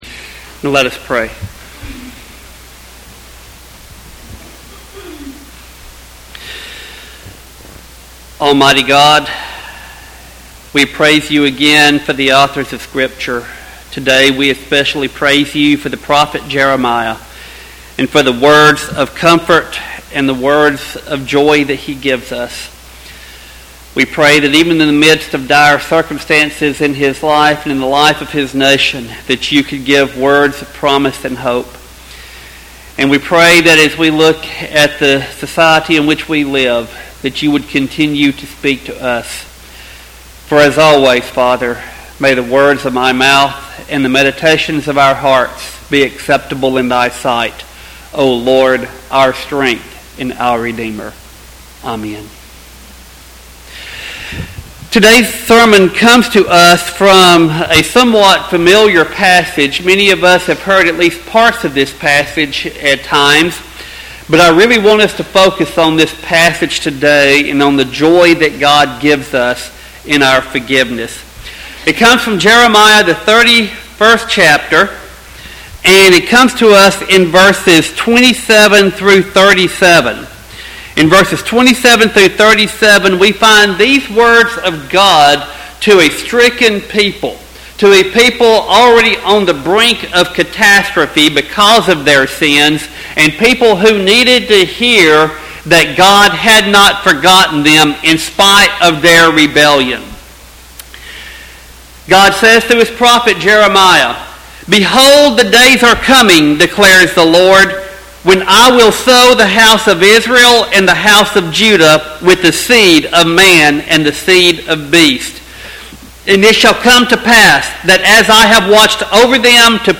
Sermon text: Jeremiah 31:27-37.